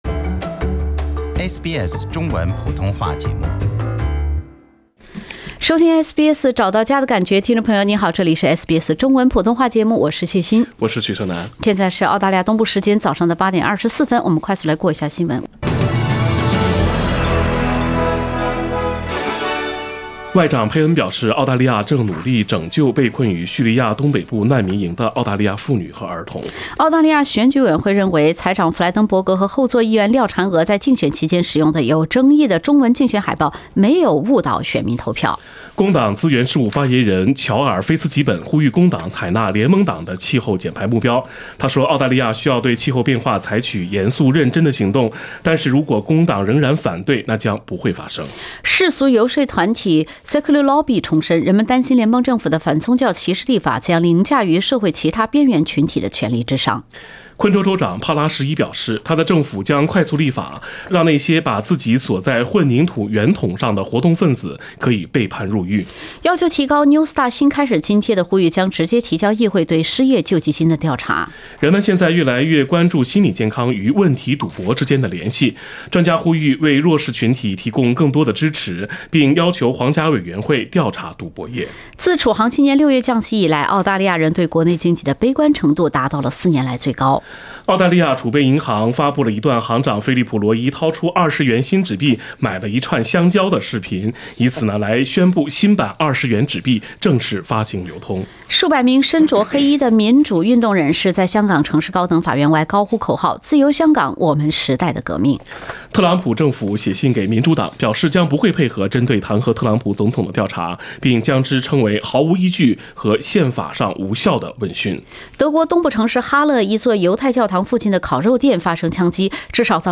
SBS早新闻 （10月10日）